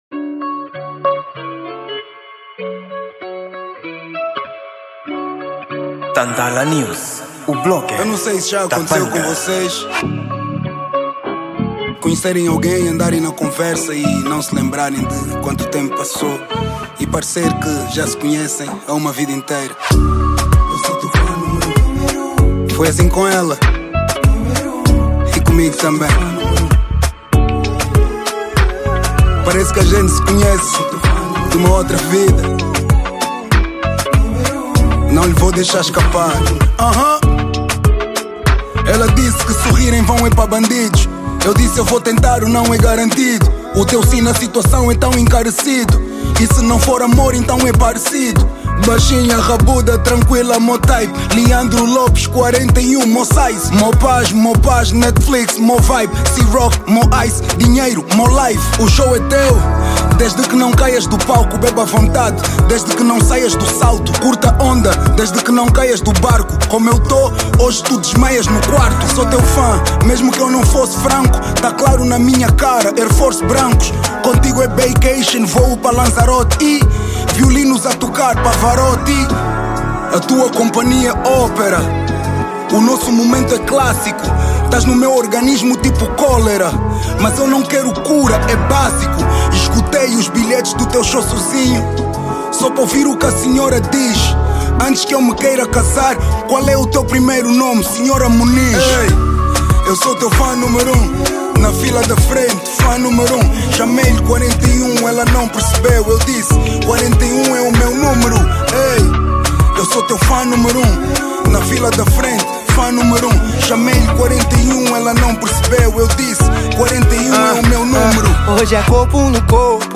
O melhor do Rap em Português
Gênero: Rap